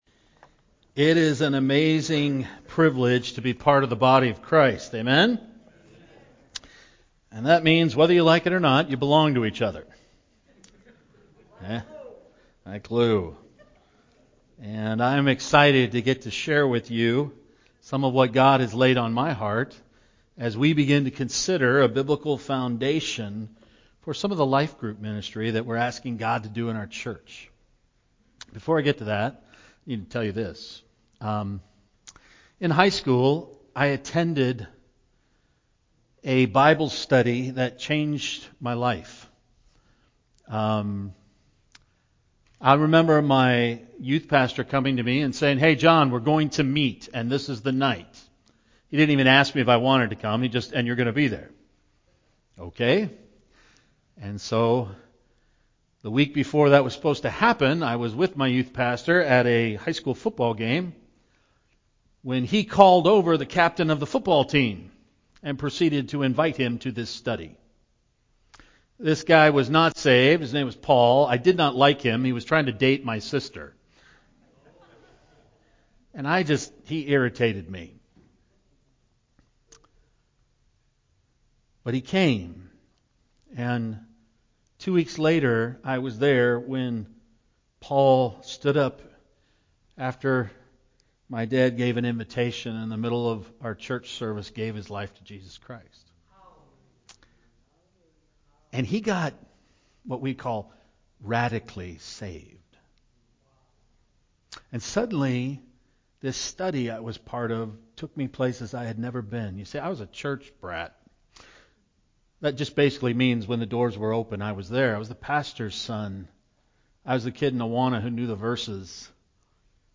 Passage: Acts 2:38-41 Service Type: Sunday morning